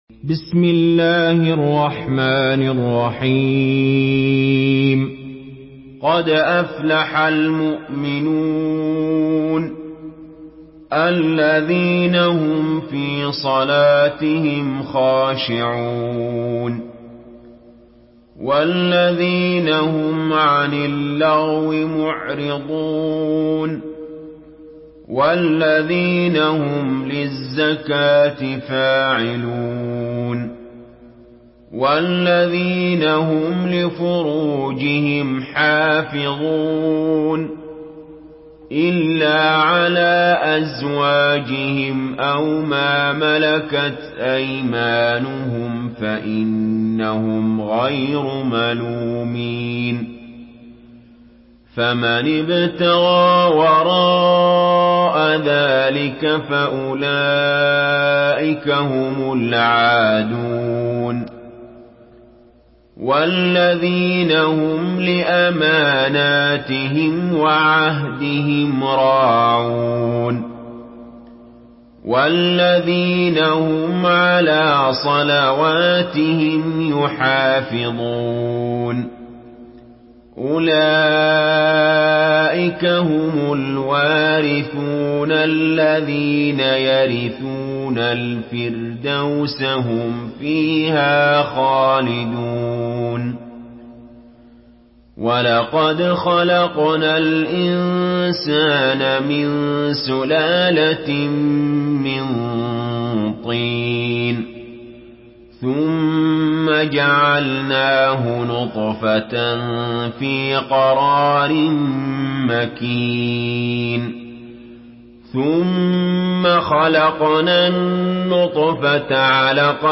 Surah Al-Muminun MP3 in the Voice of Ali Jaber in Hafs Narration
Murattal Hafs An Asim